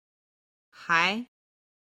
还　(hái)　まだ